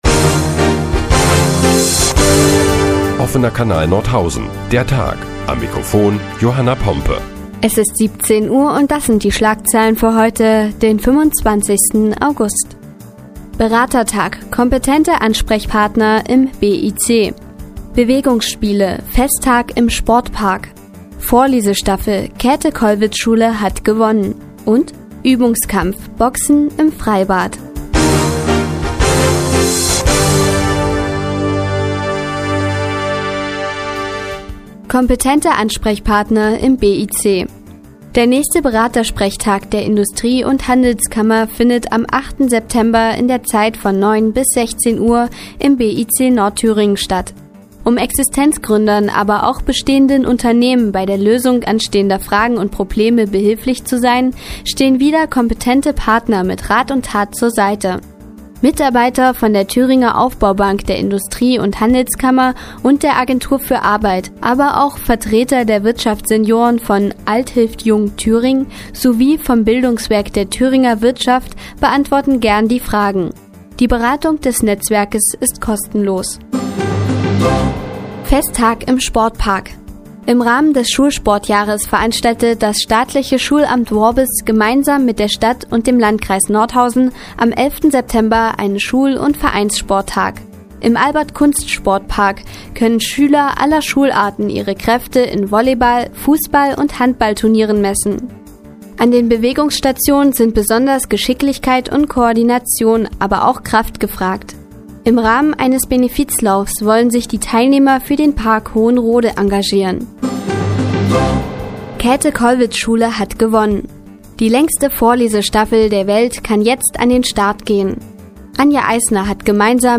Die tägliche Nachrichtensendung des OKN ist nun auch in der nnz zu hören. Heute geht es unter anderem um einen Festtag im Sportpark und um die längste Vorlesestaffel der Welt.